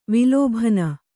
♪ vilōbhana